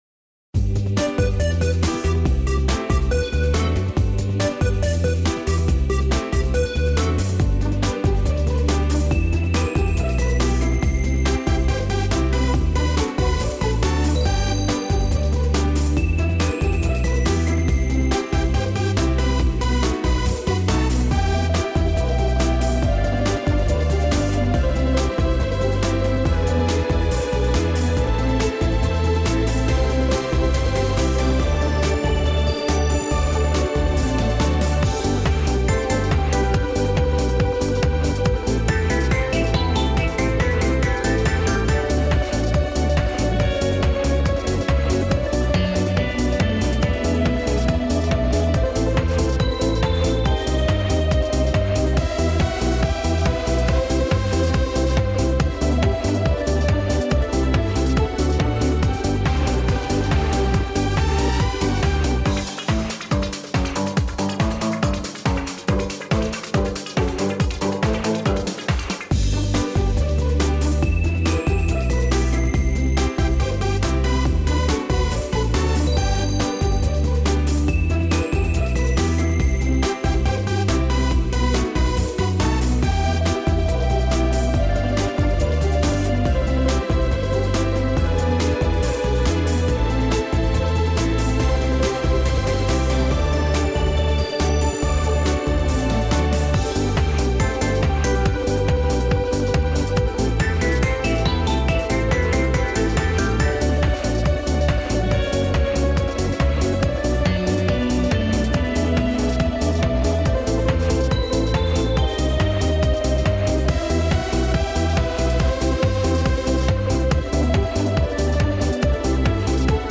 Детская_музыка_для_бега_и_упражнений.mp3